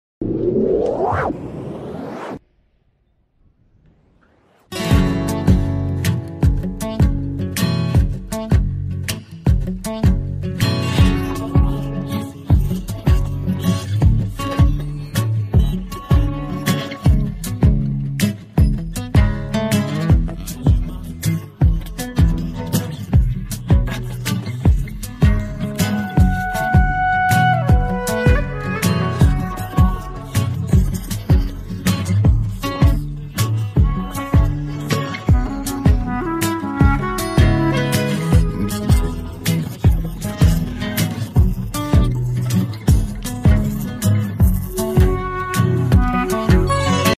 بی کلام کوتاه